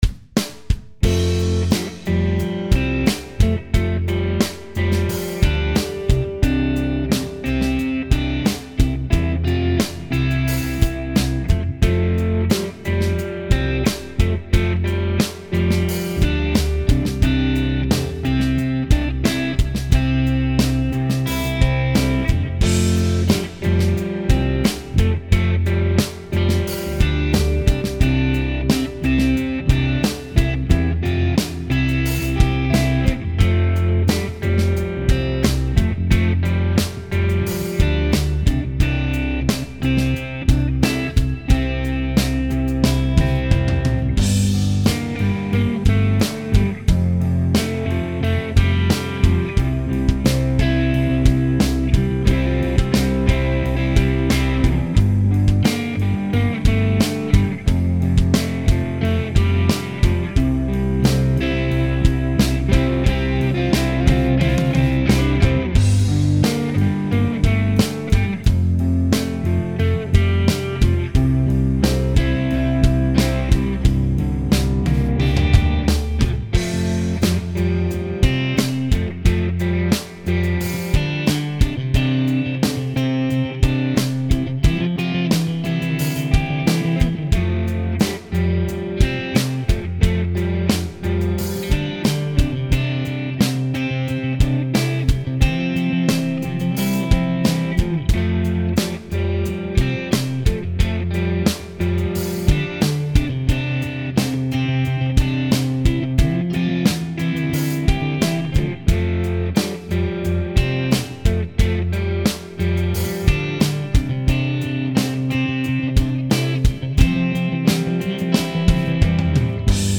I composed all of these pieces and played all guitars. Drums, keyboards, and some bass guitars I programmed via computer and some bass guitars are my playing an actual bass guitar.